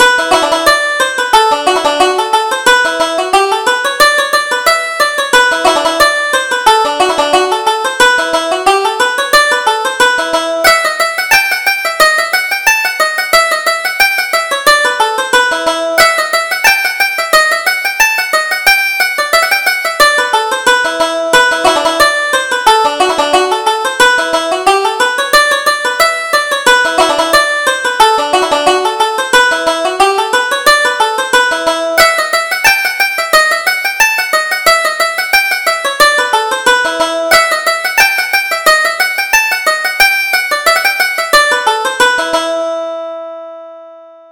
Reel: The Humors of Ballinacarrig